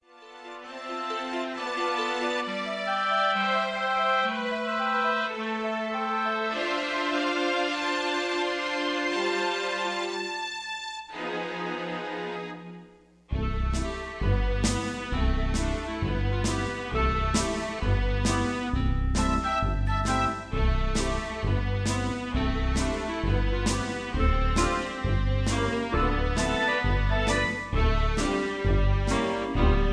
Karaoke MP3 Backing Tracks
mp3 backing tracks